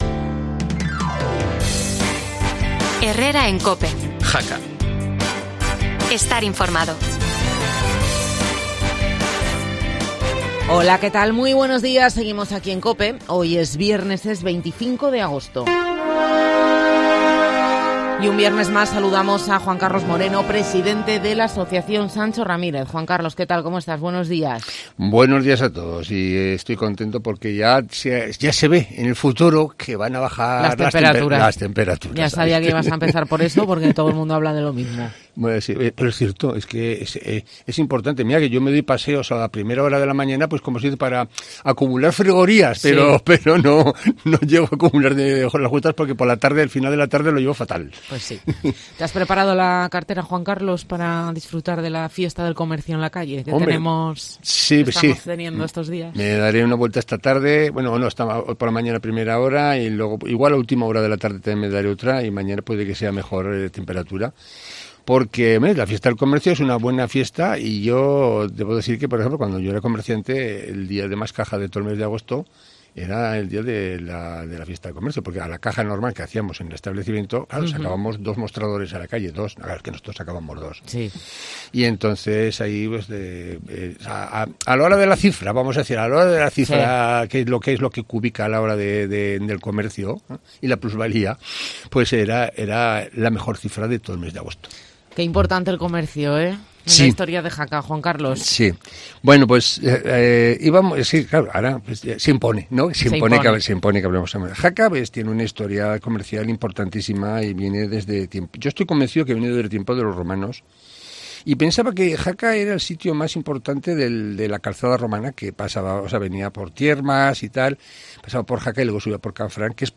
¿Quieres saber más? No te pierdas la entrevista en COPE